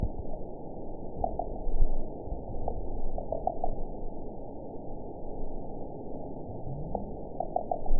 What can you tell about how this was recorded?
event 921739 date 12/18/24 time 07:41:35 GMT (11 months, 2 weeks ago) score 8.91 location TSS-AB03 detected by nrw target species NRW annotations +NRW Spectrogram: Frequency (kHz) vs. Time (s) audio not available .wav